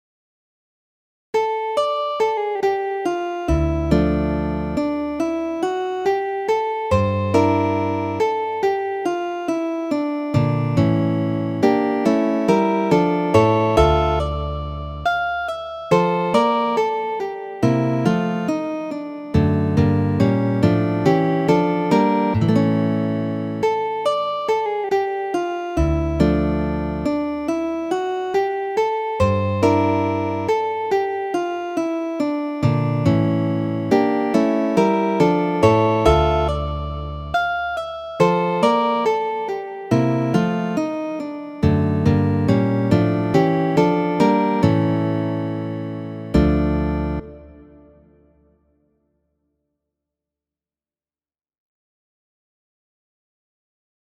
Gitaro